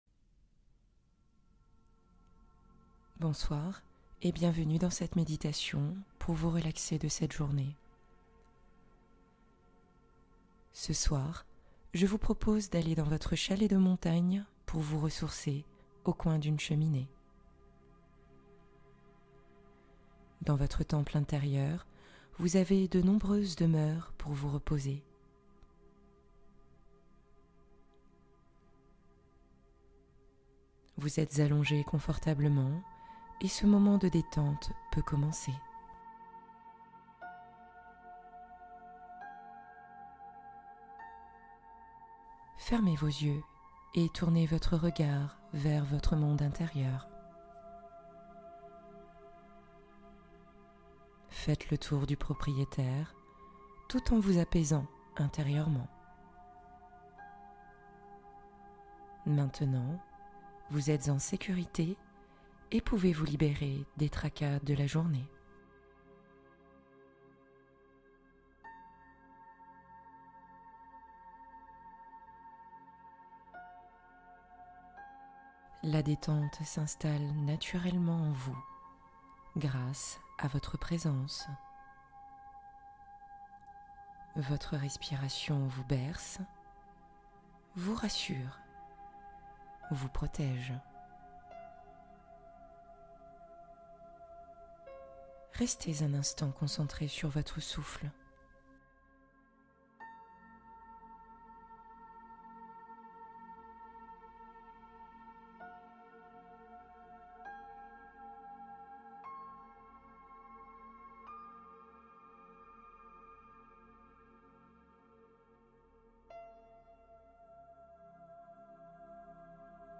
Hypnose ASMR : immersion sonore pour une paix intérieure profonde